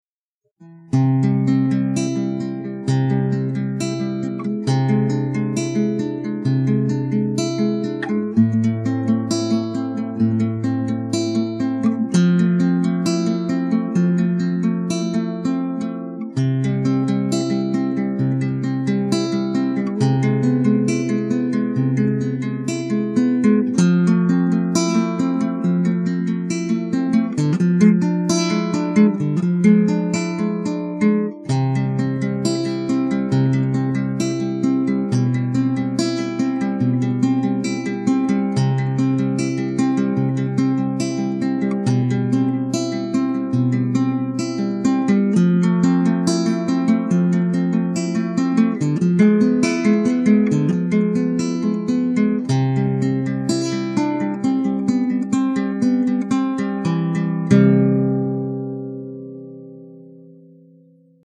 до-мажор